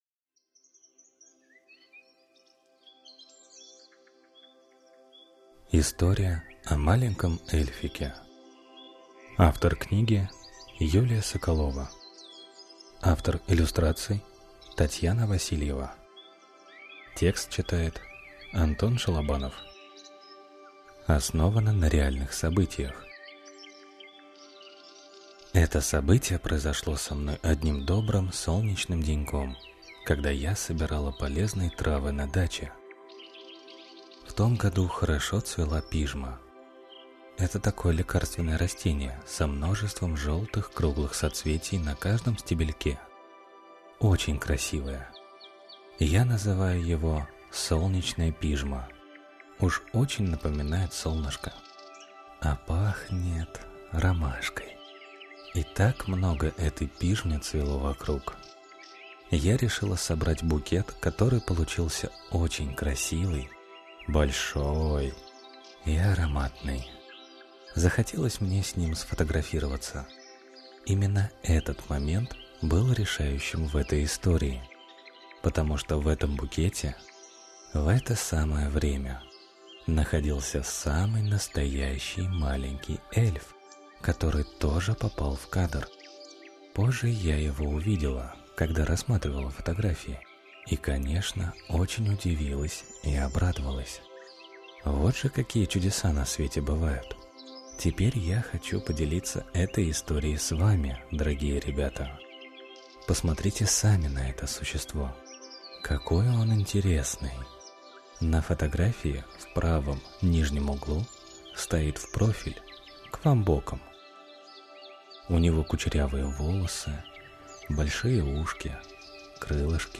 Аудиокнига История о маленьком эльфике.